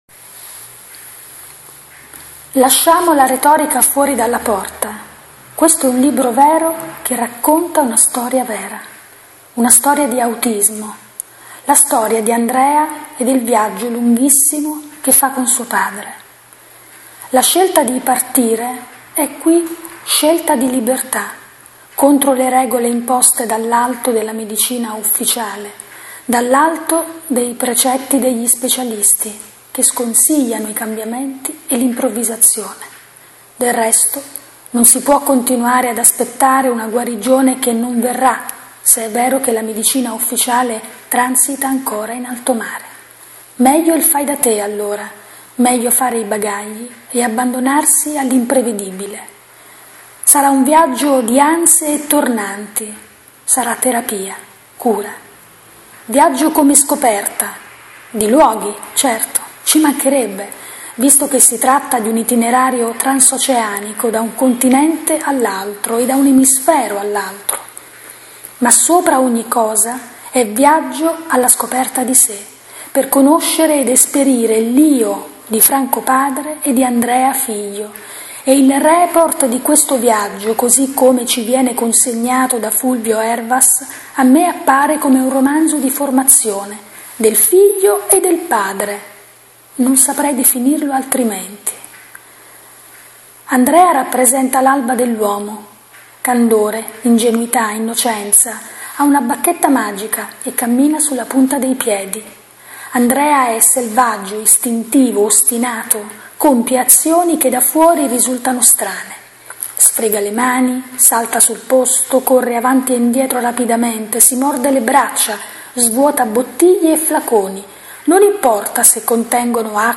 Recensione in formato audio del best seller di Fulvio Ervas, un libro fuori dal comune dedicato a una storia che, prima di qualsiasi altra cosa, � una storia vera